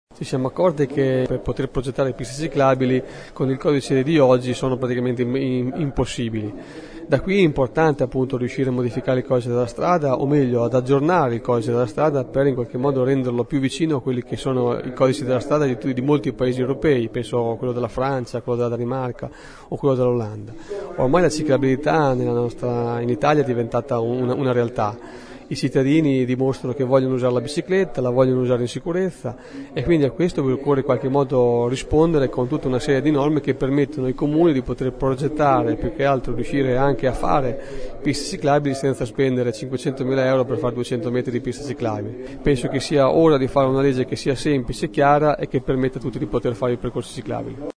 Questa è la voce dell’assessore alla Mobilità del comune di Pesaro, Andrea Biancani, che chiede la modifica del codice della strada: “Penso sia arrivato il momento di fare una legge semplice e chiara che permetta di fare le piste ciclabili”.